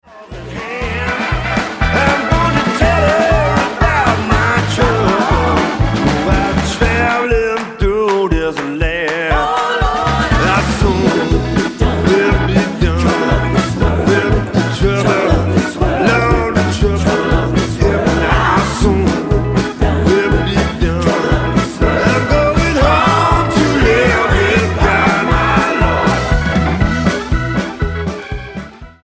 STYLE: Blues